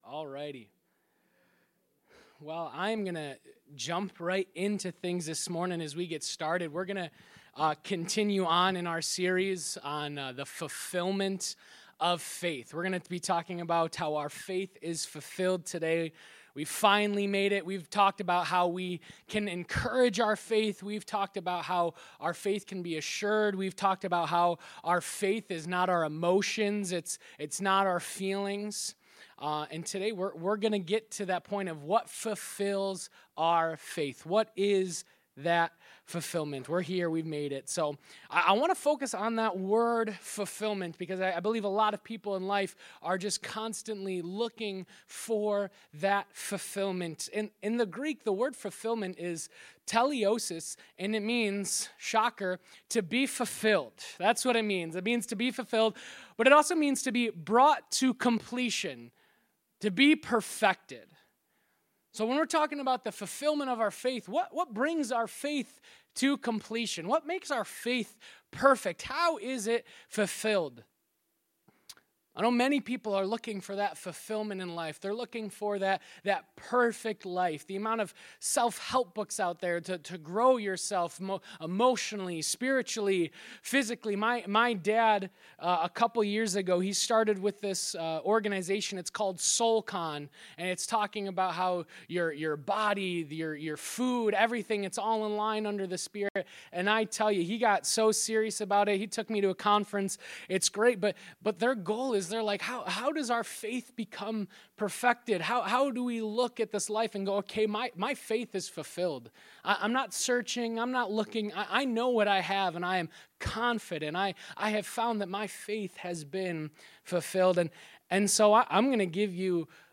1.19.25-Sermon-Audio.mp3